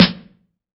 SNARE 091.wav